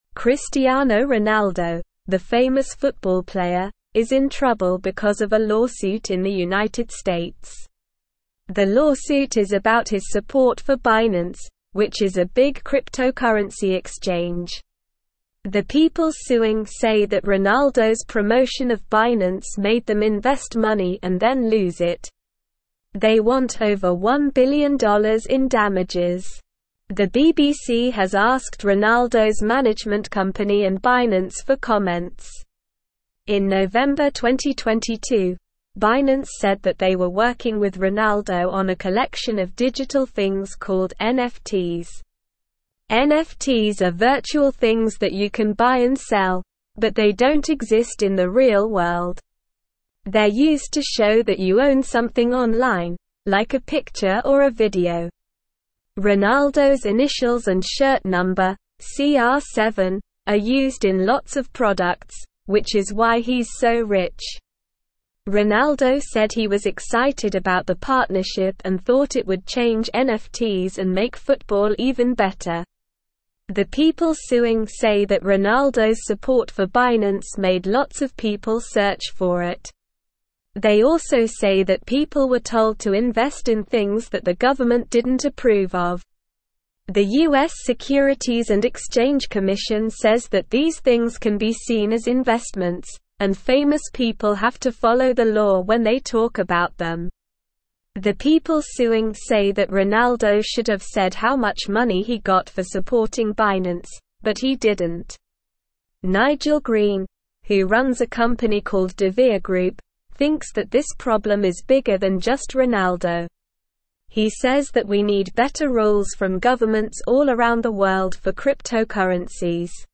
Slow
English-Newsroom-Upper-Intermediate-SLOW-Reading-Cristiano-Ronaldo-Faces-1-Billion-Lawsuit-Over-Binance-Endorsement.mp3